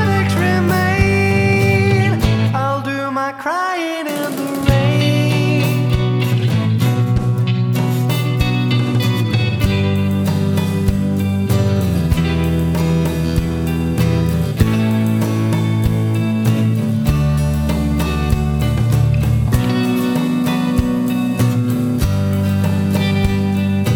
No Harmony Pop (1950s) 2:00 Buy £1.50